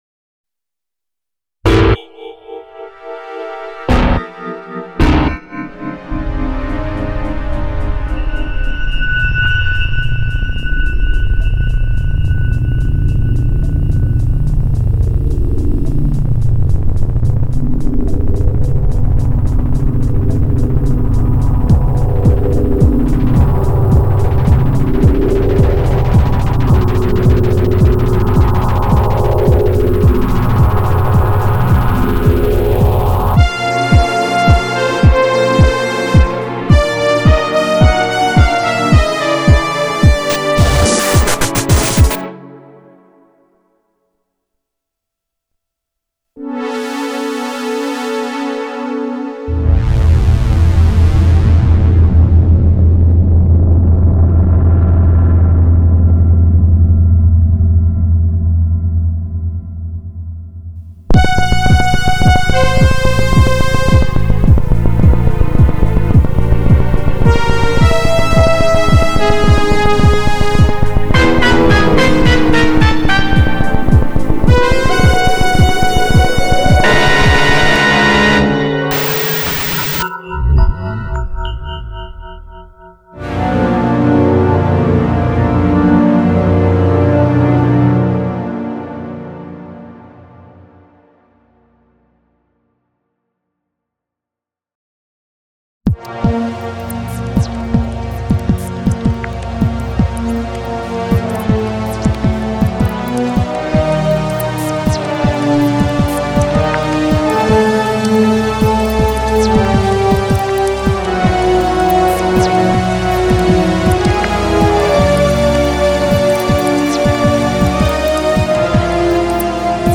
Backtrack
EverythingFallsAway_BackTrack.mp3